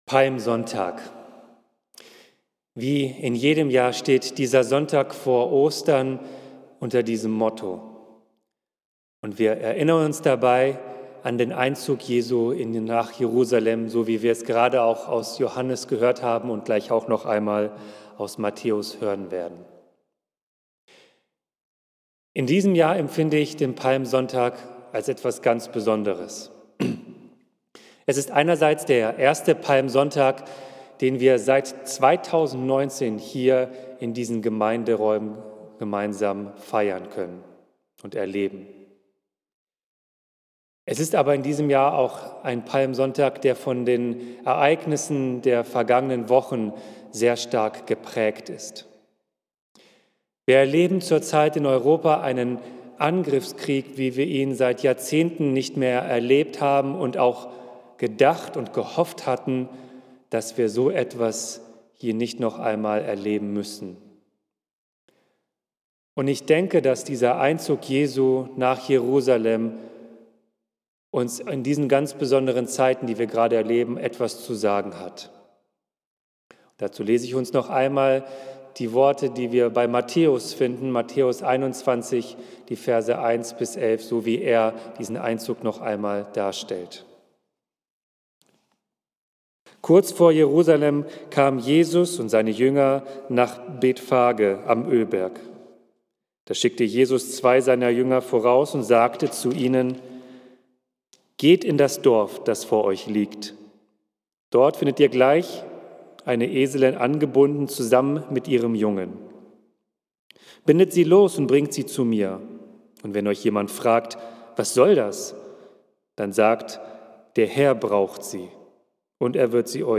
Palmsonntag im Schatten des Krieges - Predigt zu Matthäus 21, 1-11 | Bethel-Gemeinde Berlin Friedrichshain